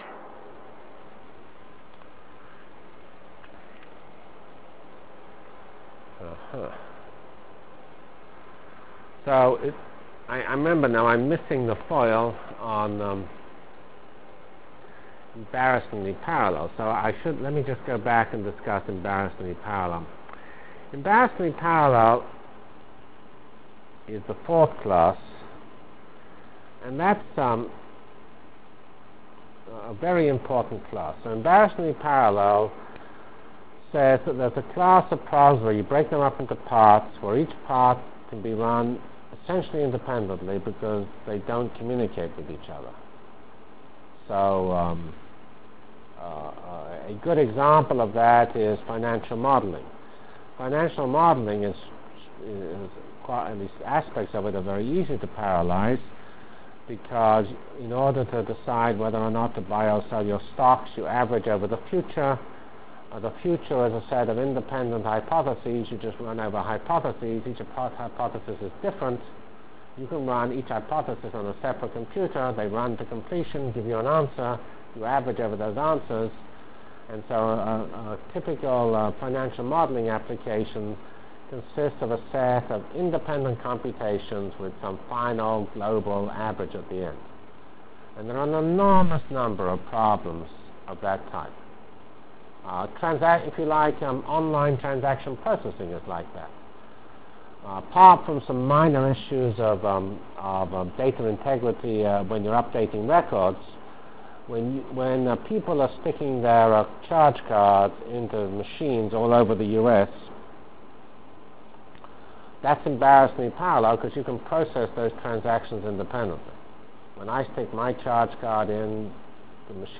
Delivered Lectures of CPS615 Basic Simulation Track for Computational Science -- 24 September 96.